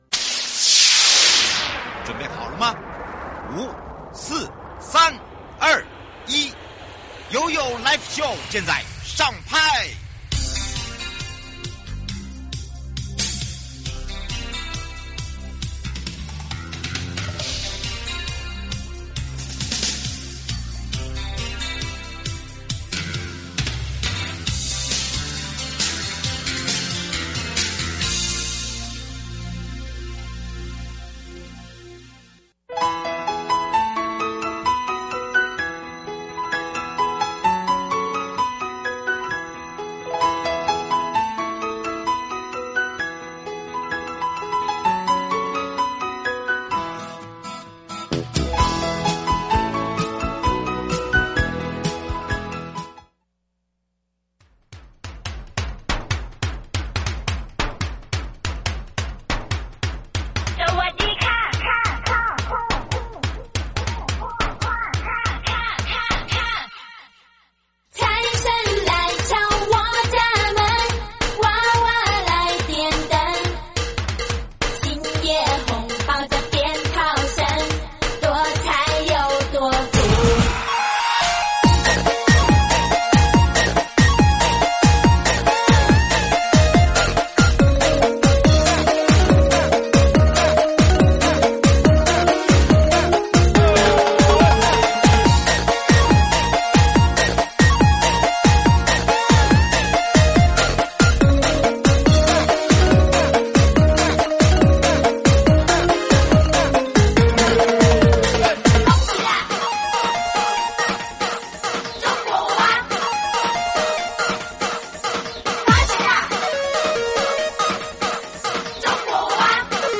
受訪者： 1. 更生保護會台北分會 2.